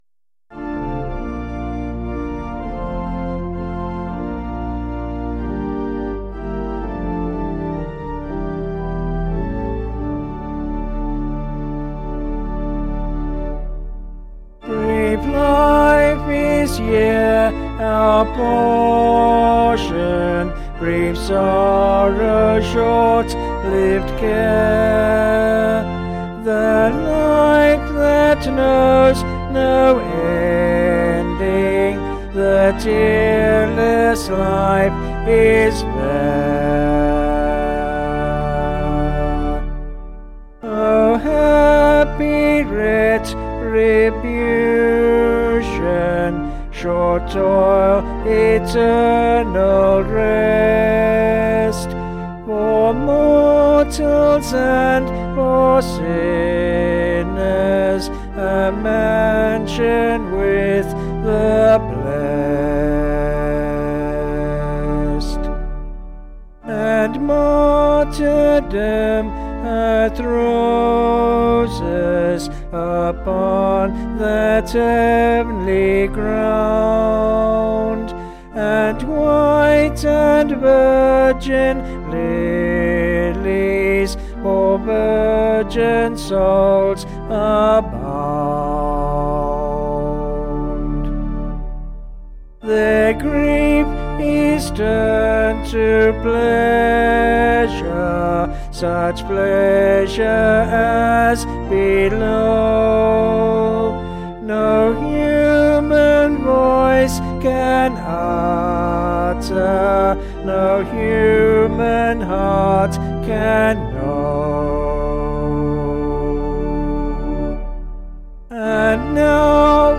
Liturgical Music
Vocals and Organ   265.1kb Sung Lyrics